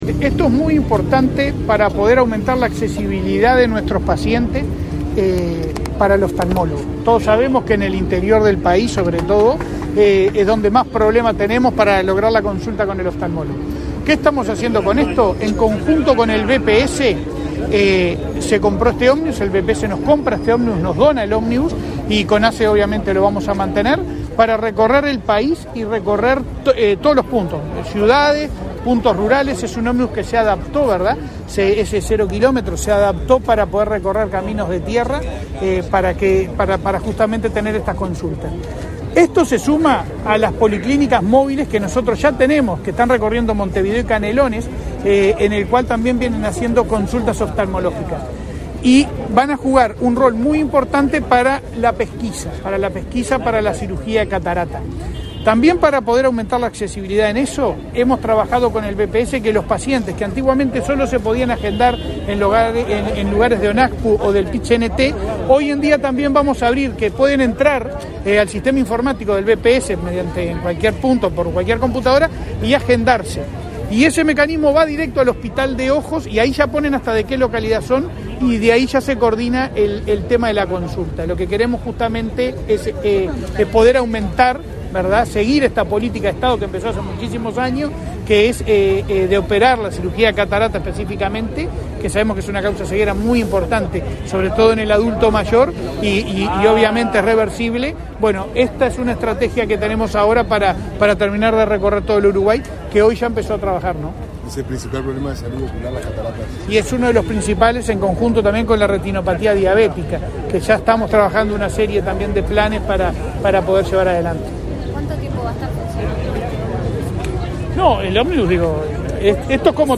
Declaraciones a la prensa del presidente de ASSE, Leonardo Cipriani
Declaraciones a la prensa del presidente de ASSE, Leonardo Cipriani 12/10/2023 Compartir Facebook X Copiar enlace WhatsApp LinkedIn El Banco de Previsión Social (BPS) y la Administración de los Servicios de Salud del Estado (ASSE) firmaron un convenio, este 12 de octubre, para la entrega en comodato al prestador de un ómnibus equipado para realizar pesquisas oftalmológicas en todo el país. Tras el evento, el titular de ASSE, Leonardo Cipriani, realizó declaraciones a la prensa.